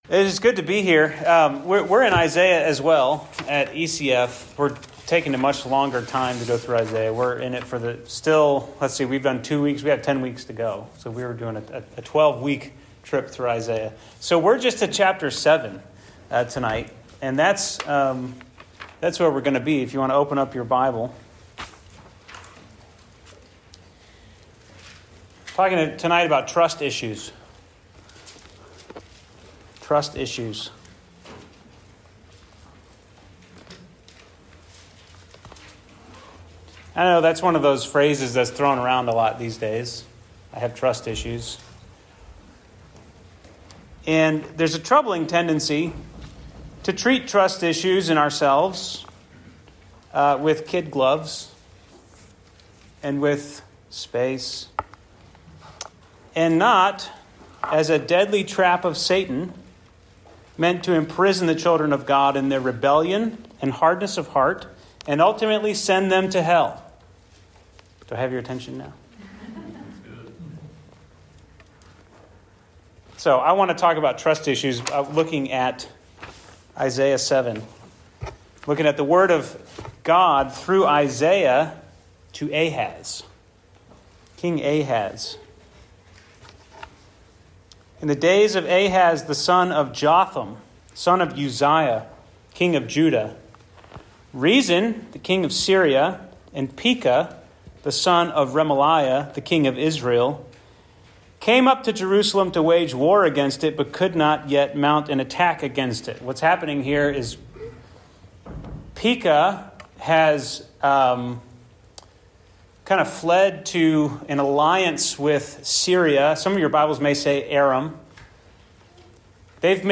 Sermon 3/19: Trust Issues – Trinity Christian Fellowship